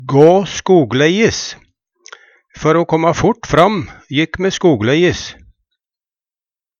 gå skogleis - Numedalsmål (en-US)